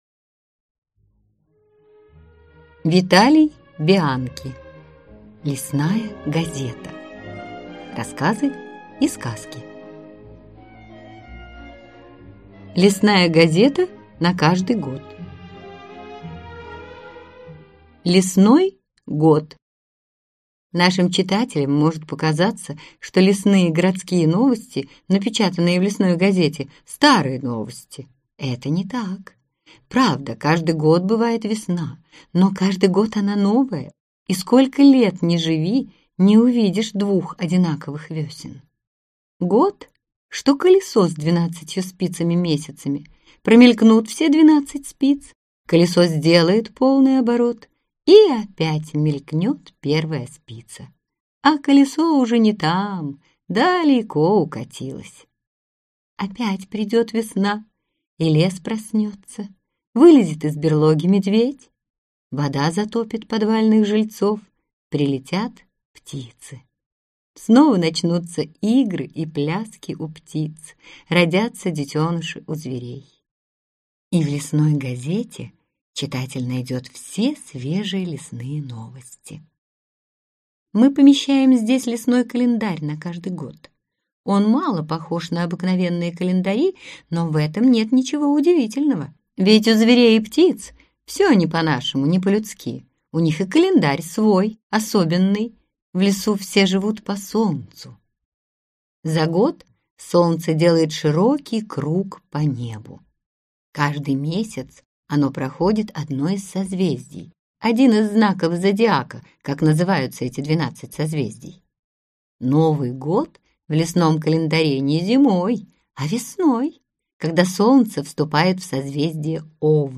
Аудиокнига Лесная газета | Библиотека аудиокниг
Прослушать и бесплатно скачать фрагмент аудиокниги